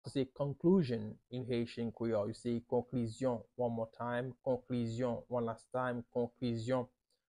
“Conclusion” in Haitian Creole – “Konklizyon” pronunciation by a native Haitian teacher
“Konklizyon” Pronunciation in Haitian Creole by a native Haitian can be heard in the audio here or in the video below:
How-to-say-Conclusion-in-Haitian-Creole-–-Konklizyon-pronunciation-by-a-native-Haitian-teacher.mp3